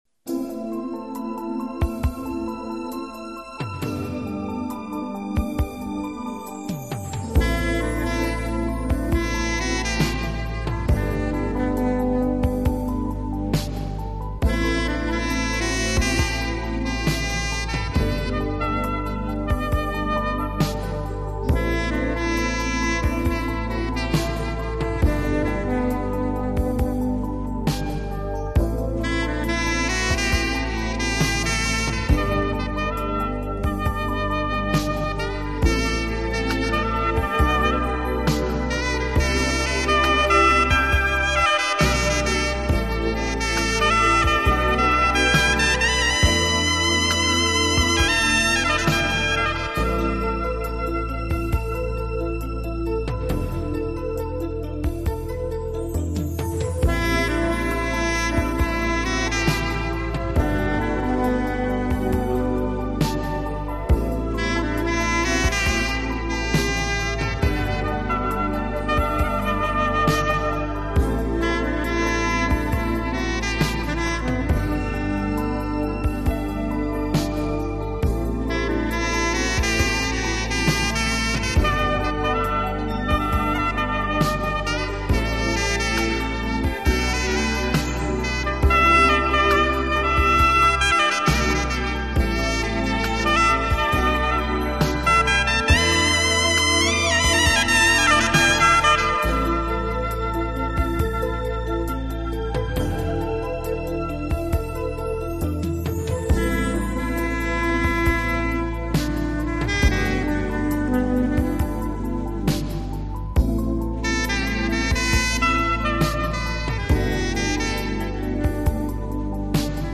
给朋友们送上一个迷人的情调音乐专辑，带领你我进入一个崭新浪漫和华
丽的音乐世界，音乐中曼妙的空间感便自然而然地完全展现！